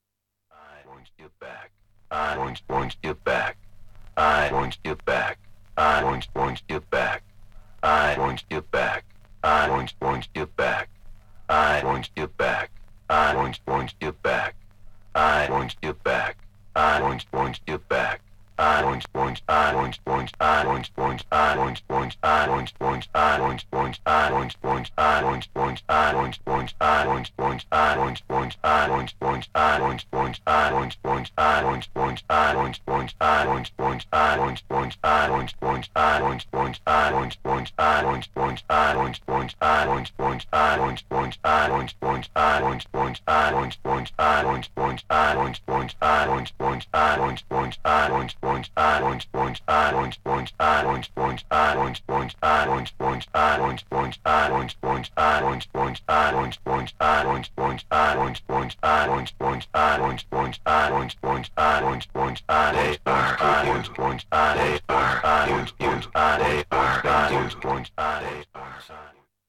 Styl: Progressive, House
D2. Acapella